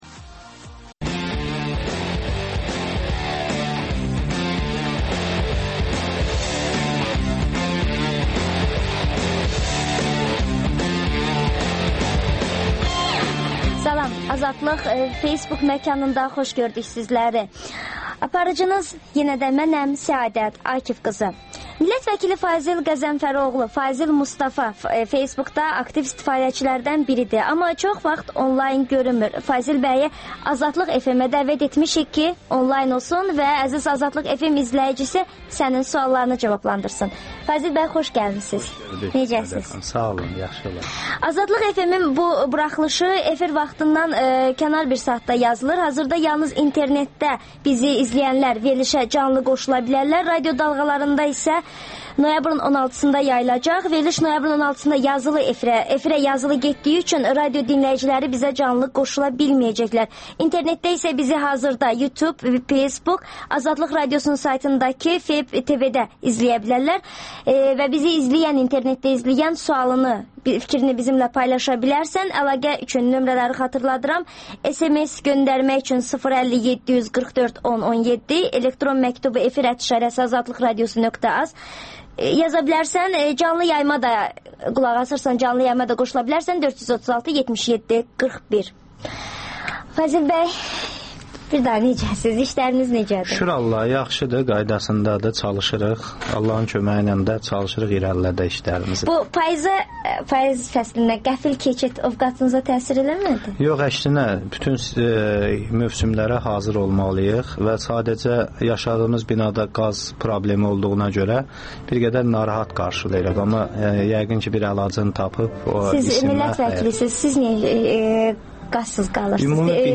Azadlıq FM - Deputat Fazil Mustafa ilə oxucuların müsahibəsi
AzadlıqFM-ə dəvəti qəbul edən Fazil Mustafa YouTube və Facebook-da canlı olaraq suallara cavab verir.